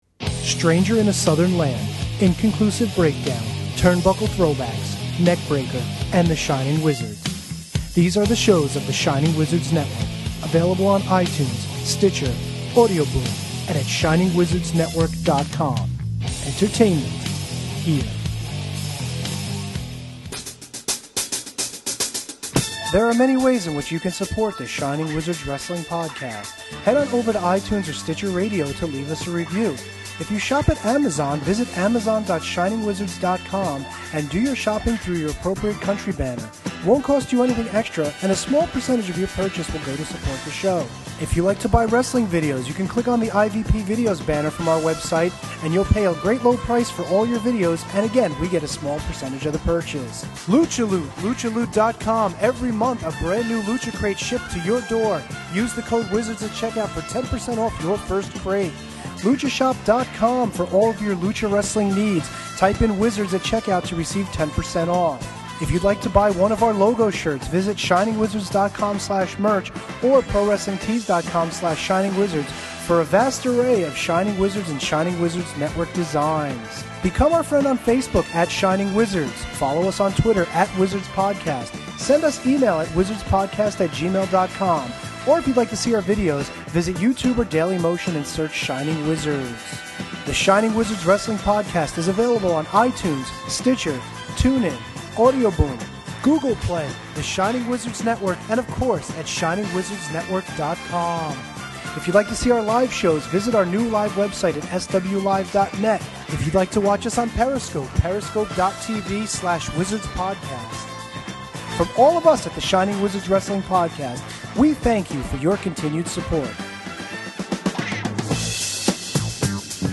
This episode was broadcast live at the ungodly hour of 6AM Eastern time, but it marked the first time the Wizards came to the masses live from outside the United States.